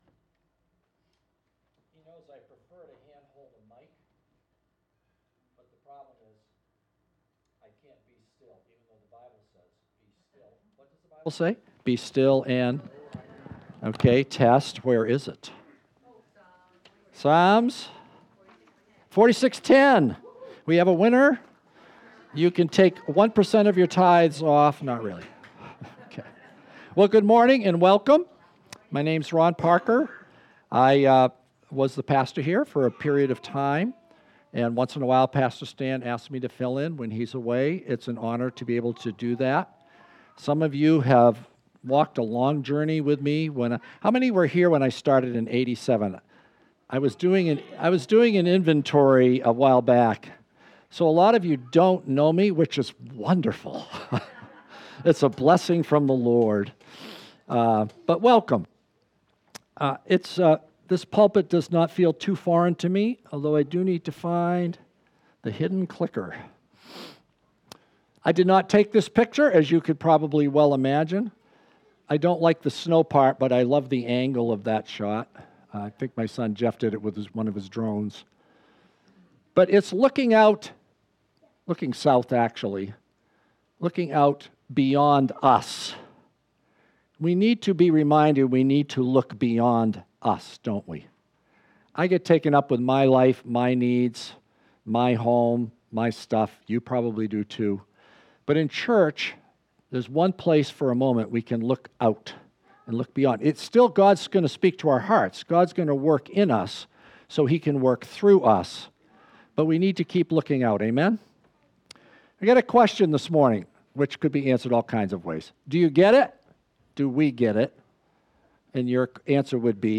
Sunday, October 26, 2025, Worship Service: “Communication”